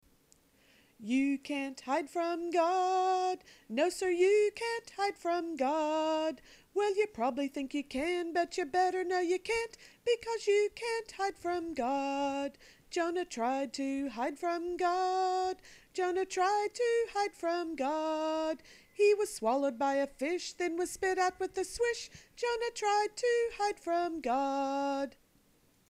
A song for young children.